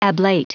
Prononciation du mot ablate en anglais (fichier audio)
Prononciation du mot : ablate